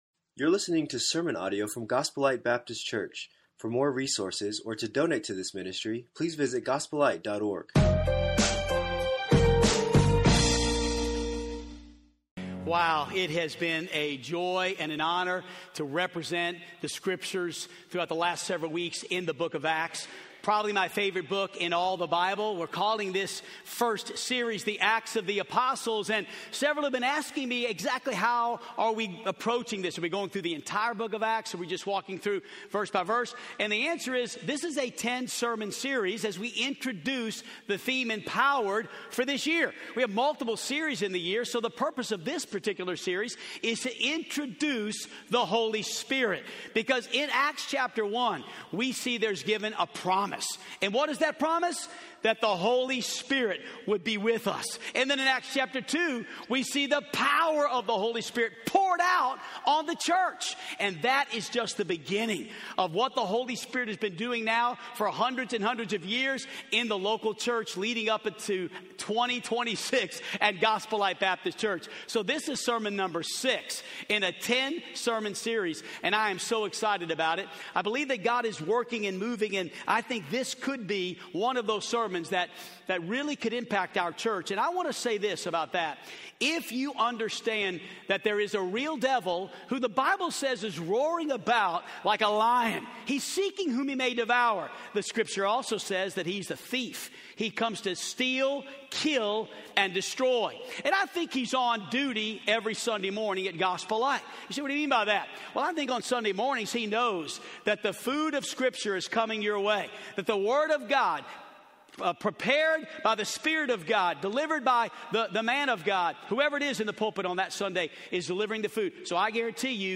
Acts of the Holy Spirit - Sermon 6